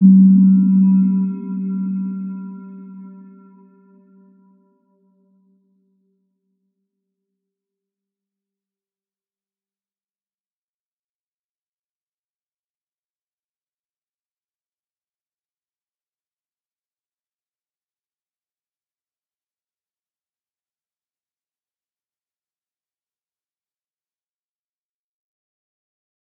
Round-Bell-G3-f.wav